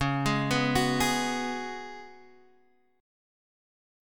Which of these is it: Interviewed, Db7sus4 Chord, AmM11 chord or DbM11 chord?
Db7sus4 Chord